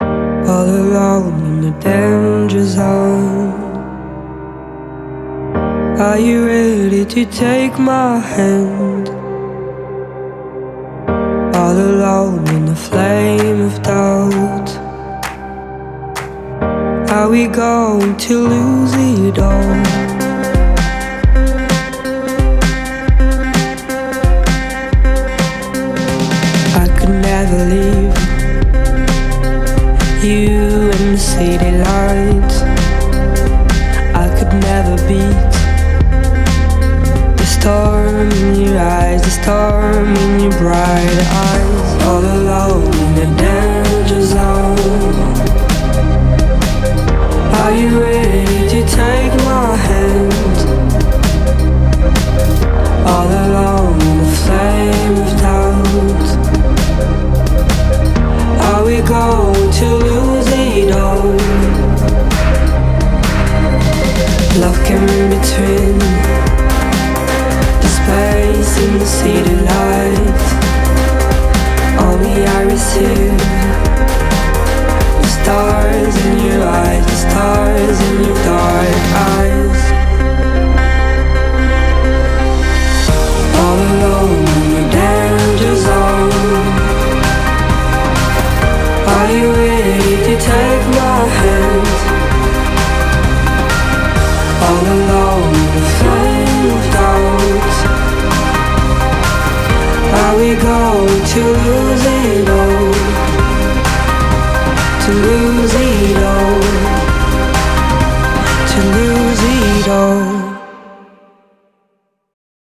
BPM65-130
MP3 QualityMusic Cut
A nice little indie pop tune from Belgium.